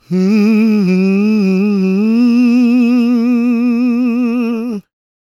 GOSPMALE007.wav